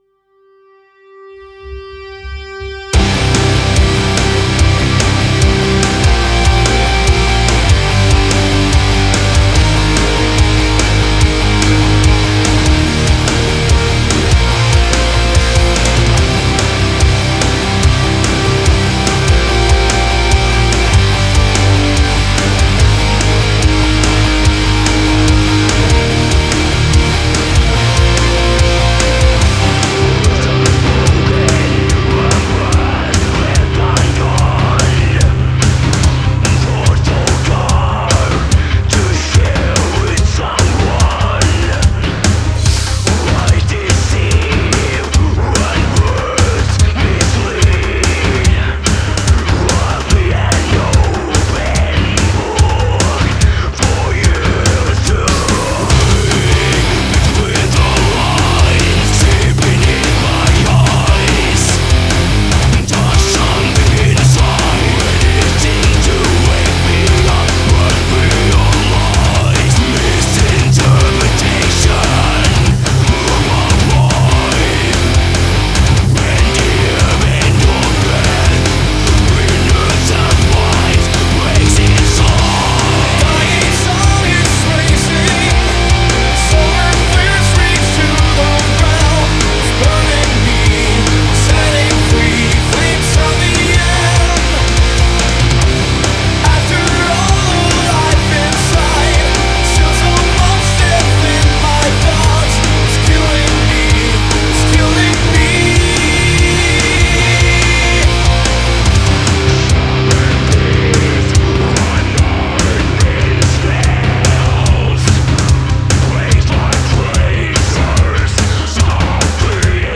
мелодичный дэт-метал или мелодичный дарк-метал.
— «меланхоличный скандинавский метал»).
вокал, гитара, ударные, клавишные
бас-гитара